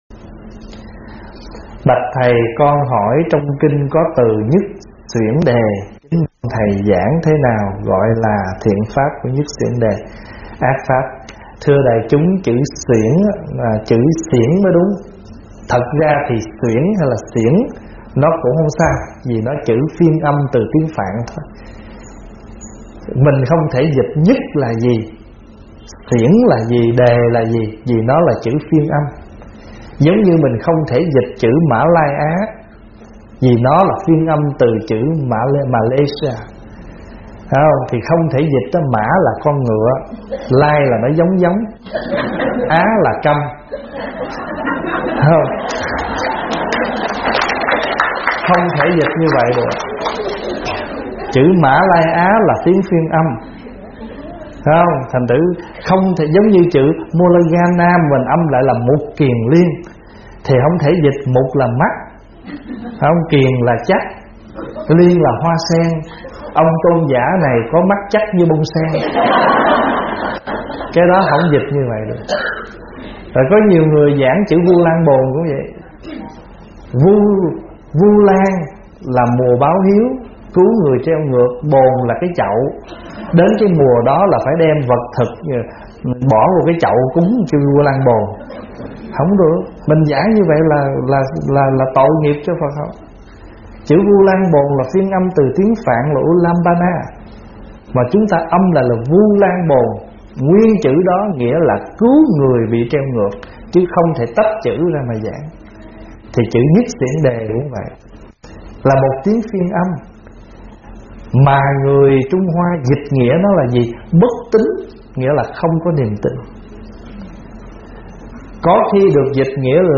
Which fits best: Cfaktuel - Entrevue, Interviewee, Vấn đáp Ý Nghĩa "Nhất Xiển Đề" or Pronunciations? Vấn đáp Ý Nghĩa "Nhất Xiển Đề"